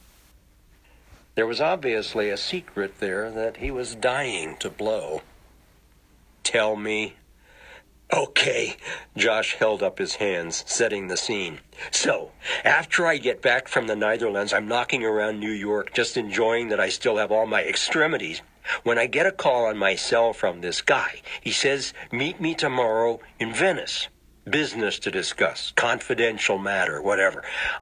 audiobooks
Three of my pet peeves: An narrator who is much too old to be reading the voices of high schoolers, a narrator who butchers an Australian accent, and a narrator whose rendition of female characters is horrendous. He had moments that weren’t terrible but anytime the female Australian character appeared, I wanted to poke my ears out.